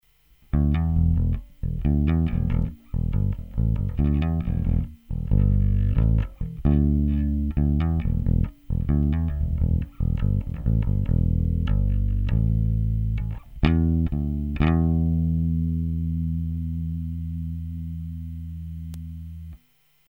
Треки записывал напрямую в линейный вход звуковой карточки без какой либо текущей и последующей обработки звука.
Bass track 3
Треки 1, 2 и 3 записаны при положении всех регулировках (High, Bass) на максимуме; 1-й трек - при включенном "Bright" (т.е. при самом верхнем положении тумблера SW 1); 3-й трек - при отсечке одной катушки хамбакера (т.е. при тумблере SW 1 в среднем положении)
bass_track3.mp3